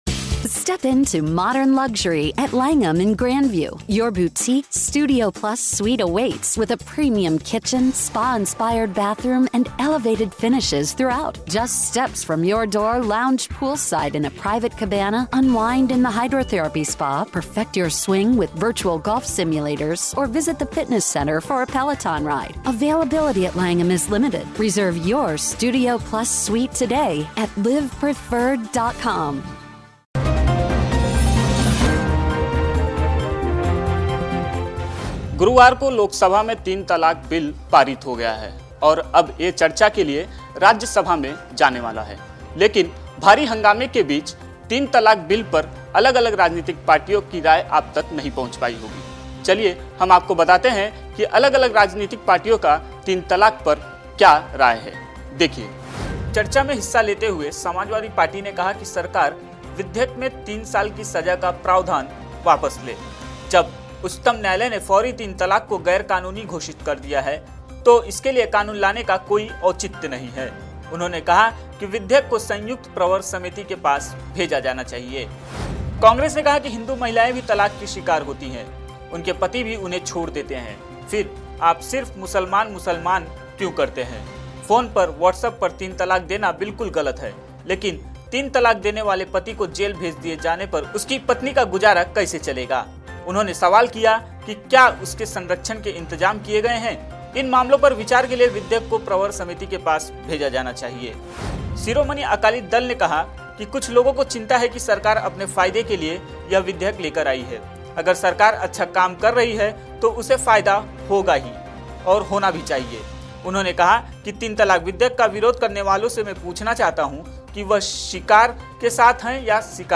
न्यूज़ रिपोर्ट - News Report Hindi / तीन तलाक पर बवाल जारी, राहुल गांधी से लेकर अखिलेश यादव की पार्टी कहती है ये